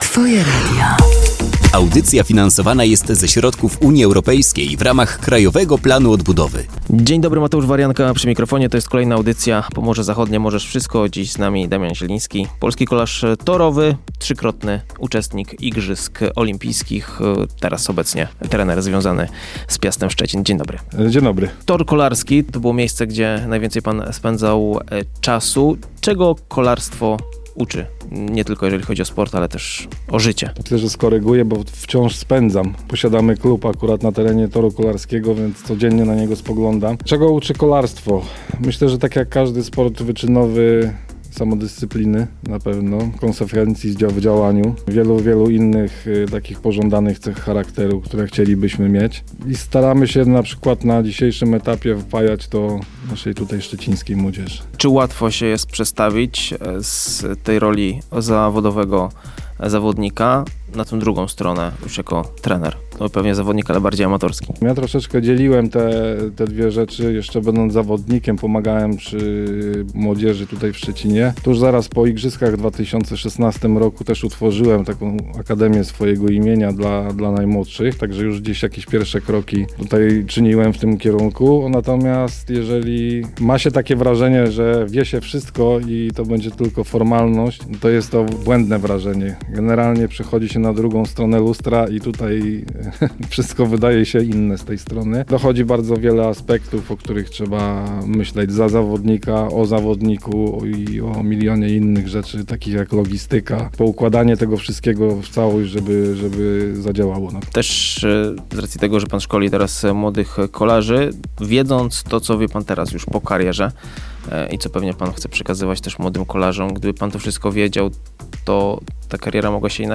Wywiad, który mogliście usłyszeć na antenie Twojego Radia, jest już dostępny w formie podcastu!
Posłuchaj inspirującej rozmowy o pasji, determinacji i spełnianiu marzeń – już teraz w podcaście